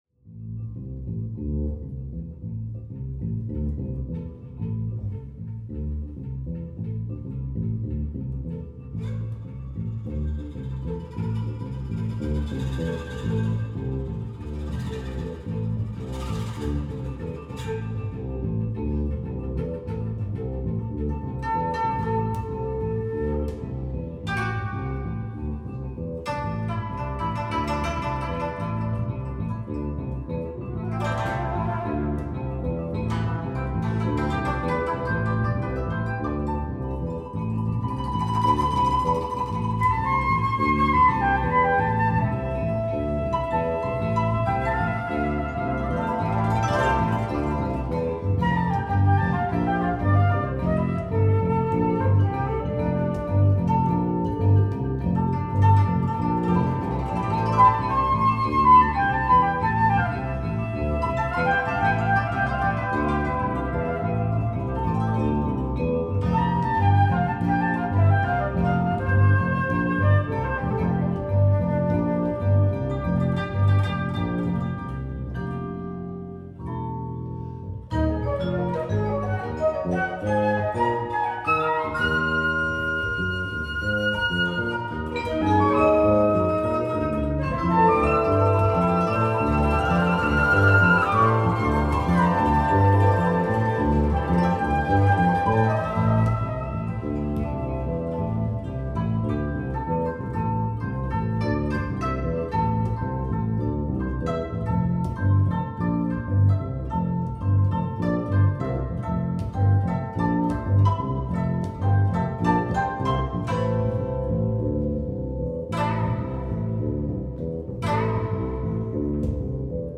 live 2015